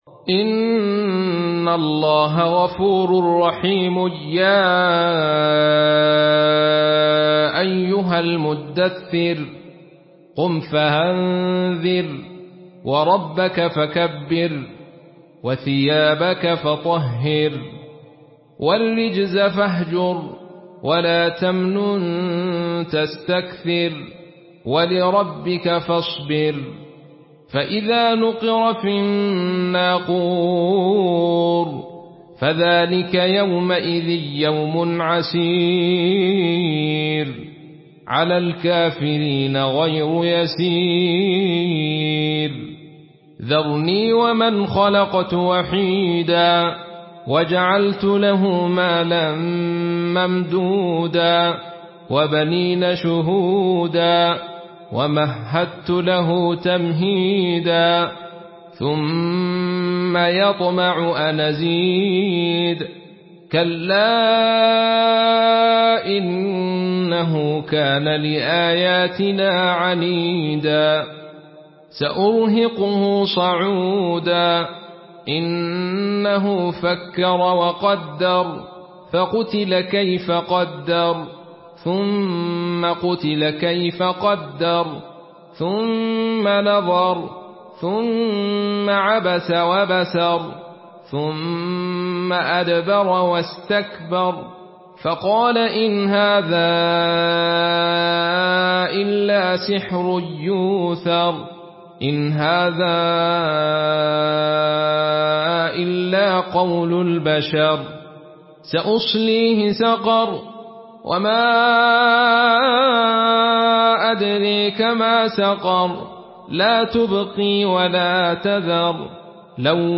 Une récitation touchante et belle des versets coraniques par la narration Khalaf An Hamza.